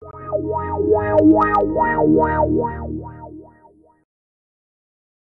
Play, download and share Wobbly XP original sound button!!!!
windows-xp-wobbly-sound.mp3